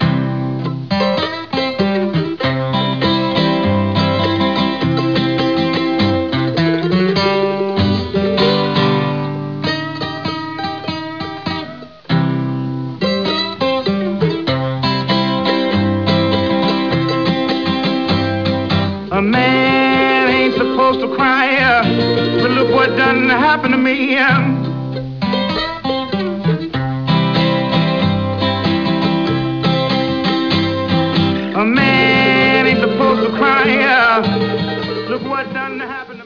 Vocals and guitar
Piano, backing vocals
harmonica, backing vocals